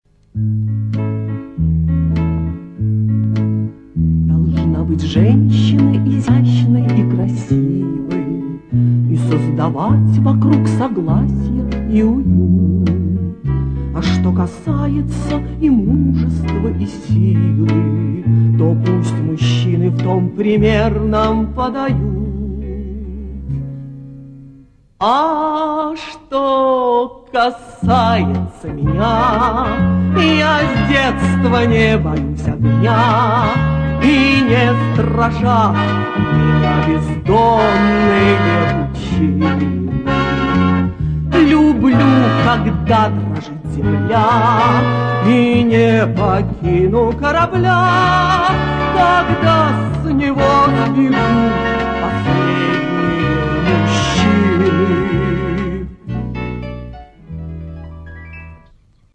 Певцы
Режим: Stereo